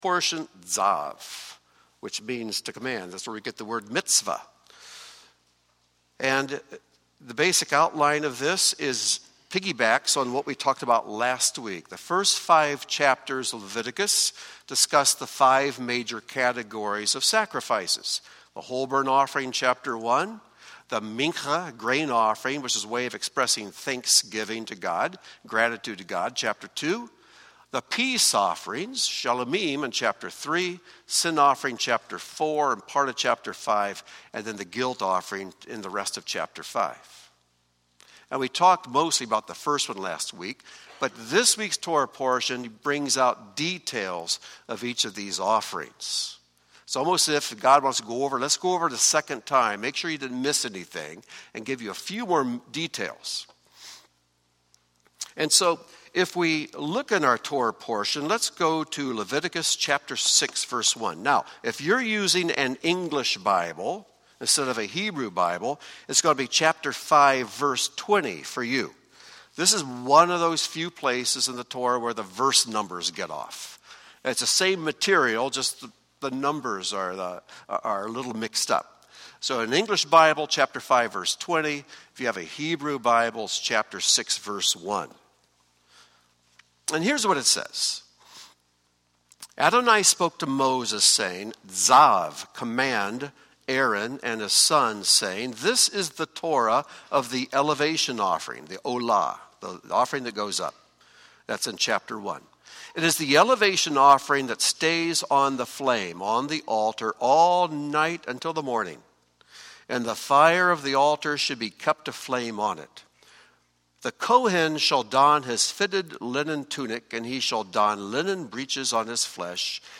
Join Beth Tikkun for a teaching on Torah portion Tzav as focue on the human being's unique choice to engage the body or the spirit.